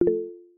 Play Google Meet Message Sound - SoundBoardGuy
Play, download and share Google Meet message sound original sound button!!!!
google-meet-message-sound.mp3